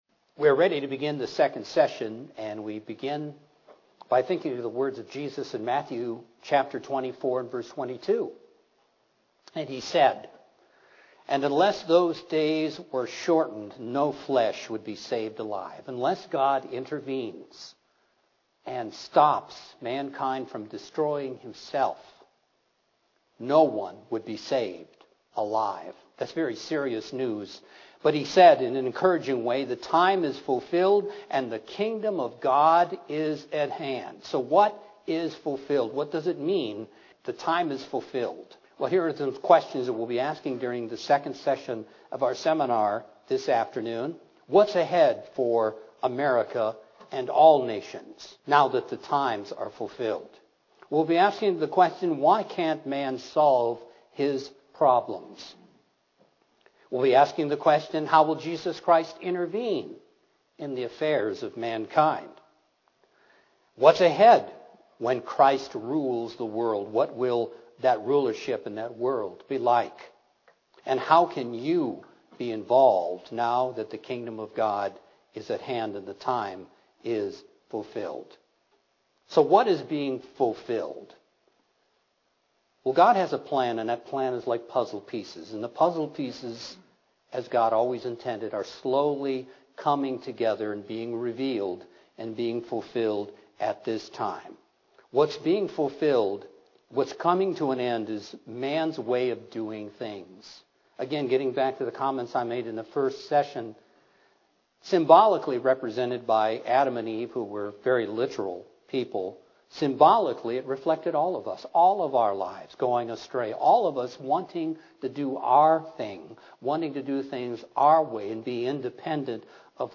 What did Jesus mean that the time is fulfilled and the Kingdom is at hand? Find the answer to these and other questions in this Kingdom of God seminar.